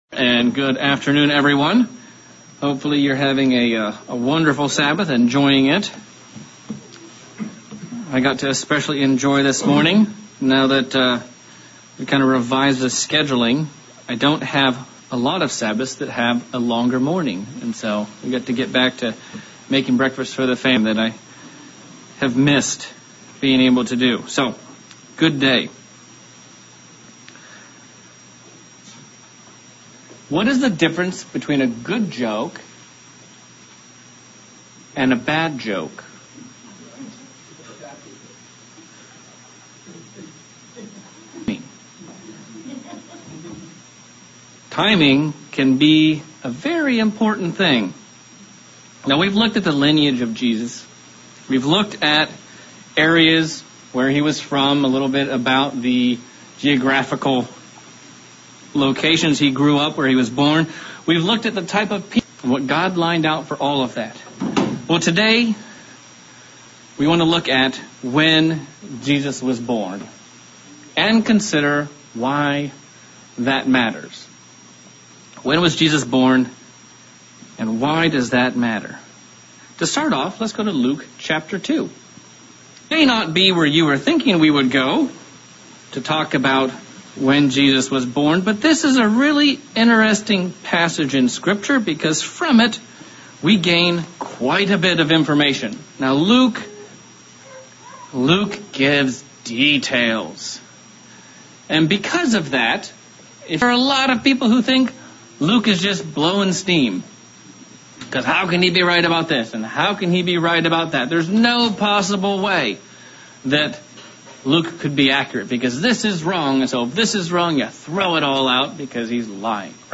Sermon looking at the specifics of timings and events around the birth of Christ.